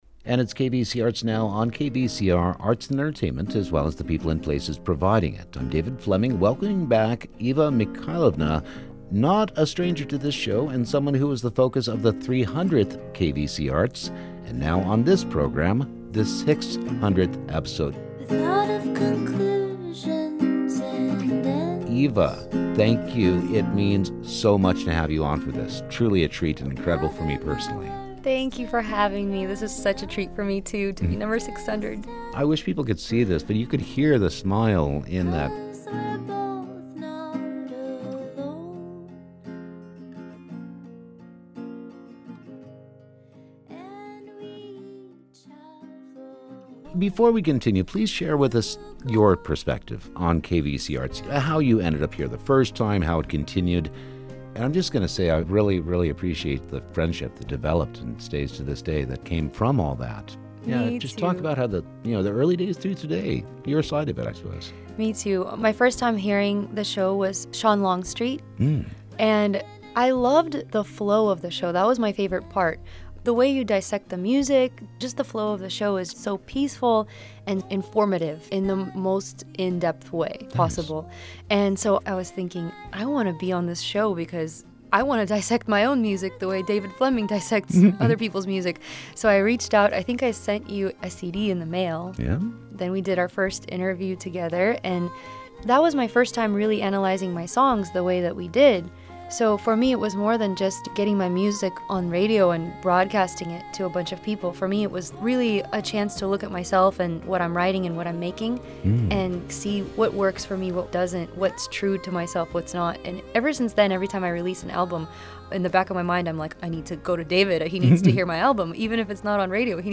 Interviews with authors, producers, visual artists and musicians, spotlighting a classic album or a brand new release.